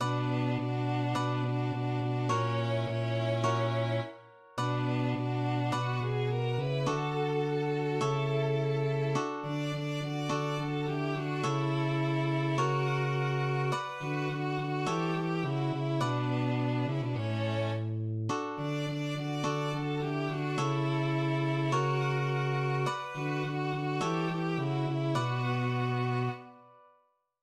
Spottlied auf einen ängstlichen Marschall